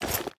Minecraft Version Minecraft Version 25w18a Latest Release | Latest Snapshot 25w18a / assets / minecraft / sounds / item / armor / equip_gold3.ogg Compare With Compare With Latest Release | Latest Snapshot
equip_gold3.ogg